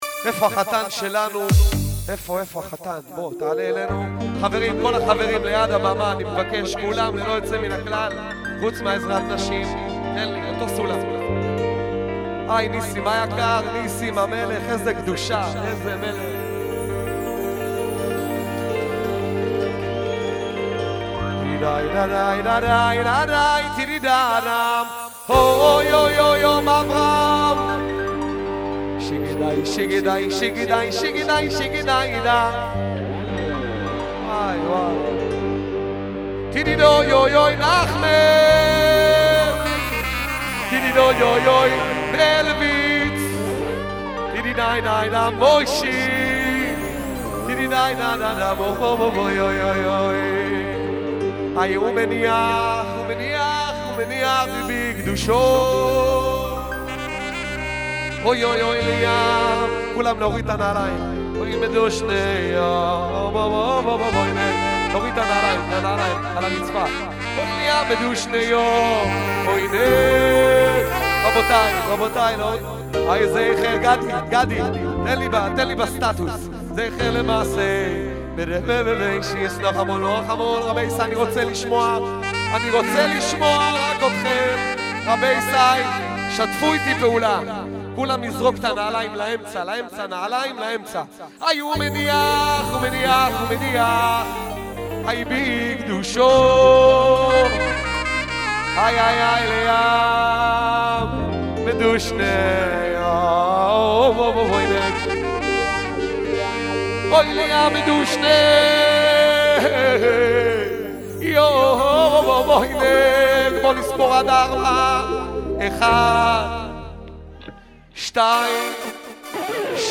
יש לו מקצבים איכותיים מאד
קלידן